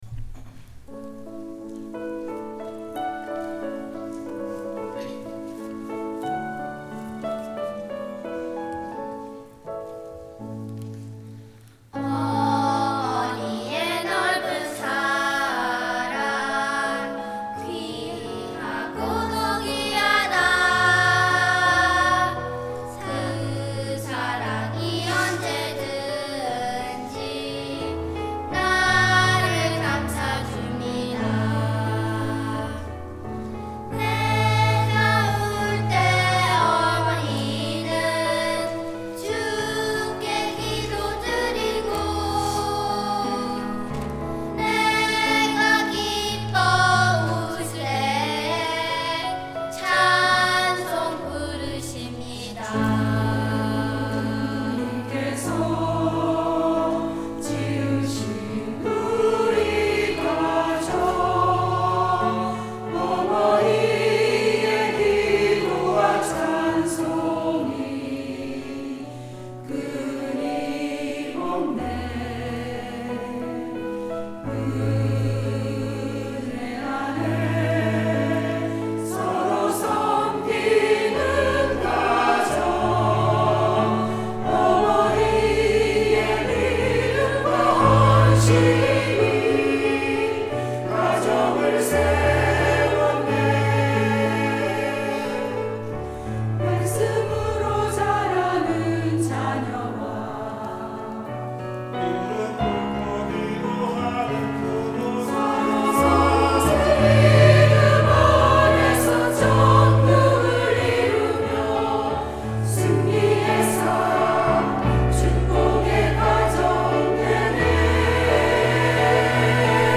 5월 12일 성가대 찬양
구두회 작곡, 진선미 편곡
반석 연합 찬양대